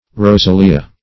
Rosalia \Ro*sa"li*a\, n. [Cf. F. rosalie.] (Mus.)